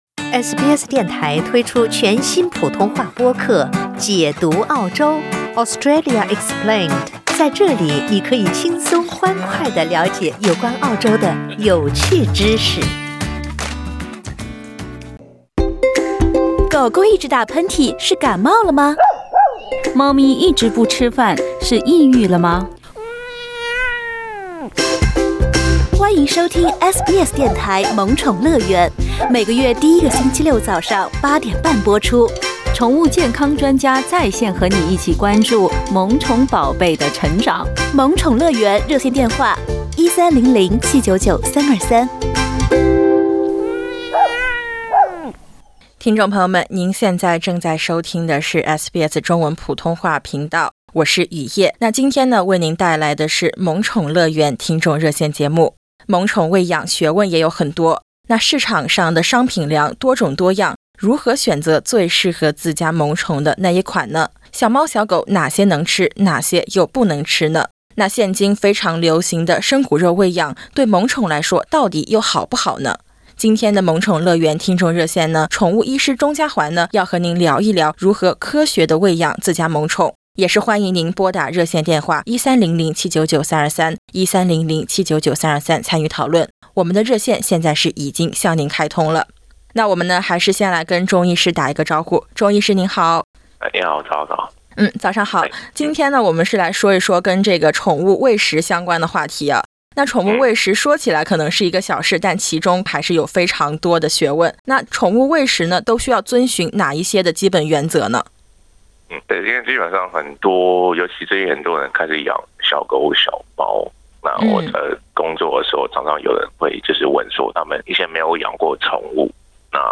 欢迎点击图片音频，收听完整采访。